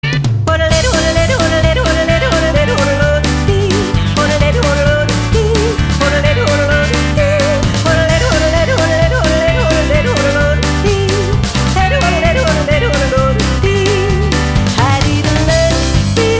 • Качество: 320, Stereo
забавные
озорные
Йодль
саундтрек